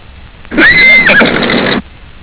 Horse
HORSE.wav